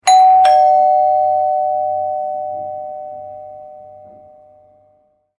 Notification Sounds / Sound Effects
House-bell-sound-effect.mp3